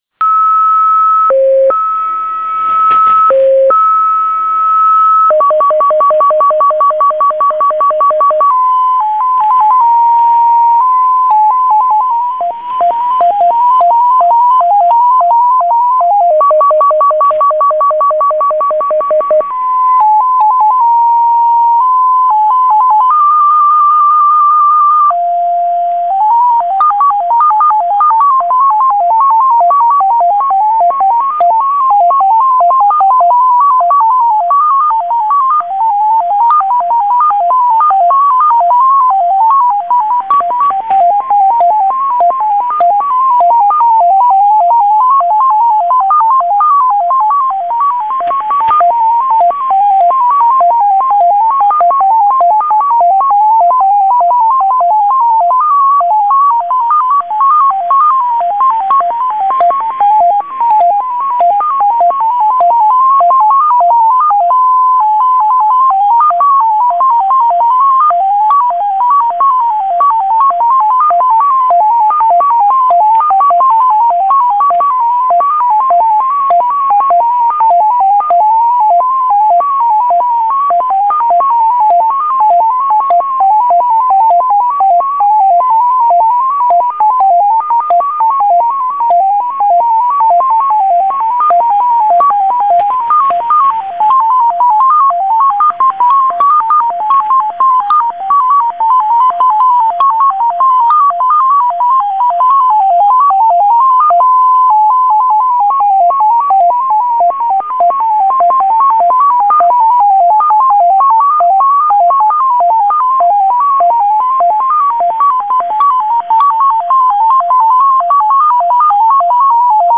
• Enigma Designation XPA, also known as MFSK-20, CIS MFSK-17, and CIS MFSK-20, is a 17-tone MFSK signal said to have originated from Russian Intelligence and Foreign Ministry stations: